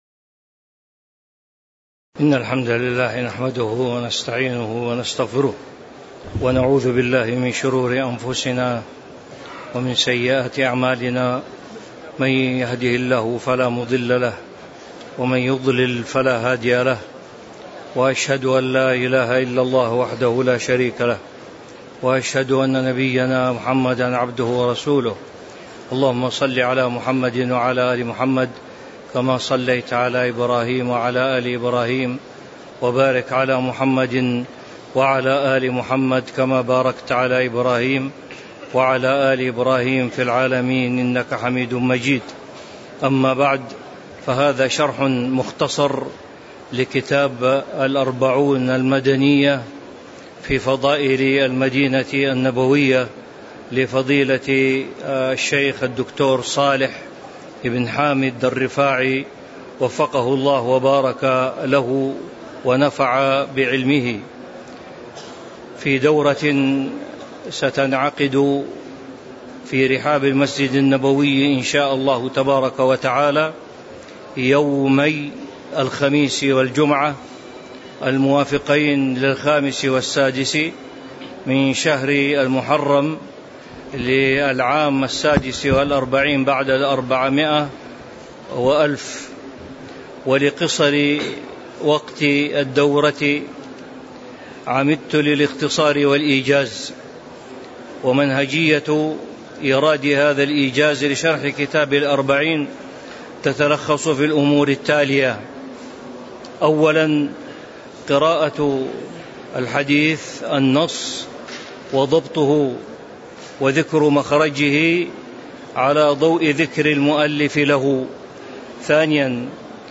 تاريخ النشر ٥ محرم ١٤٤٦ هـ المكان: المسجد النبوي الشيخ